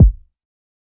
dopefiend kick.wav